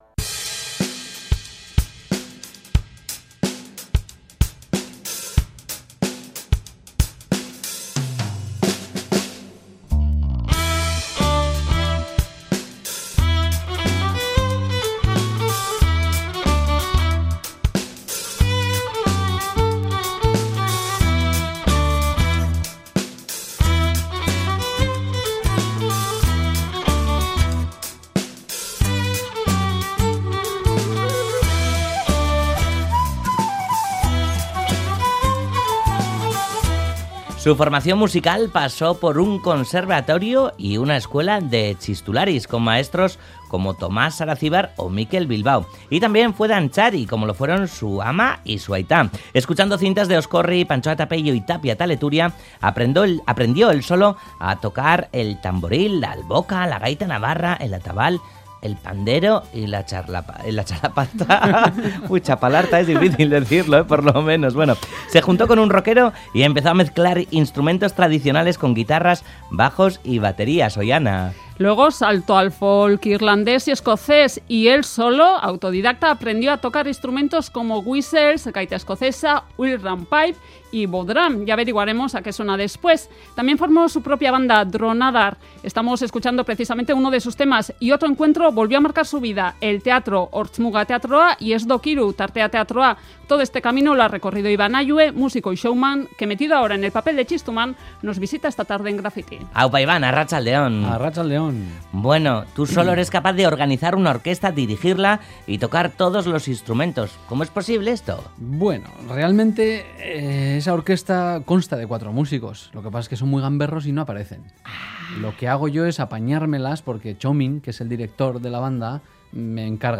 plática y música en directo con un artista multiinstrumentista